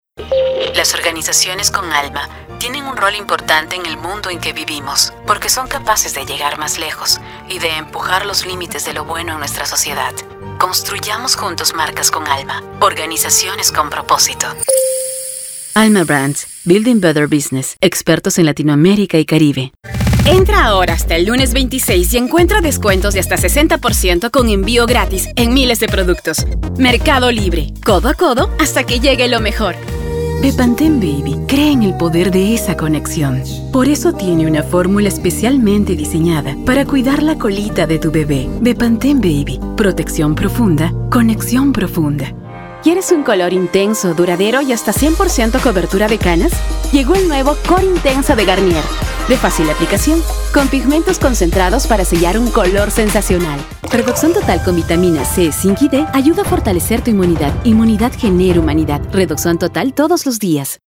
Female
Approachable, Assured, Authoritative, Confident, Conversational, Cool, Corporate, Engaging, Friendly, Natural, Reassuring, Smooth, Soft, Upbeat, Versatile, Warm
Peruvian (native)
Audio equipment: Apollo x6, Soundproof and acoustically treated recording booth